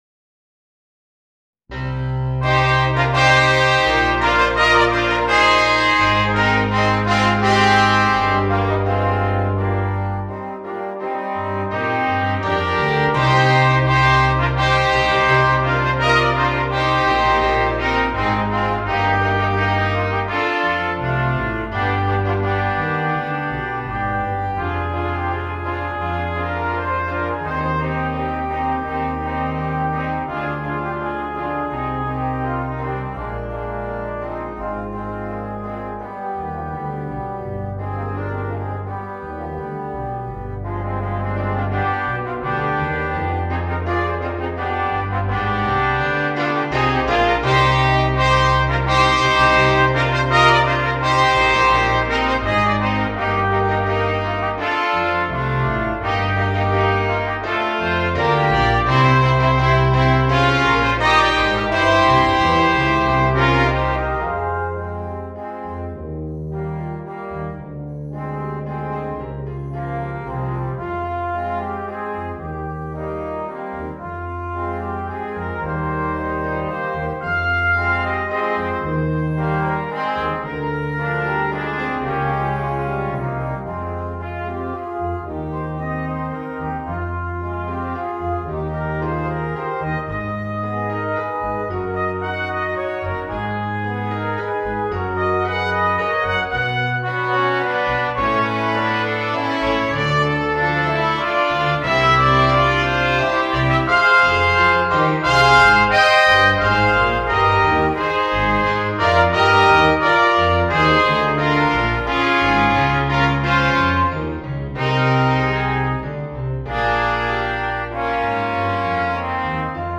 Gattung: Brass Quartet
Besetzung: Ensemblemusik für 4 Blechbläser